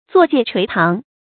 坐戒垂堂 zuò jiè chuí táng
坐戒垂堂发音
成语注音 ㄗㄨㄛˋ ㄐㄧㄝ ˋ ㄔㄨㄟˊ ㄊㄤˊ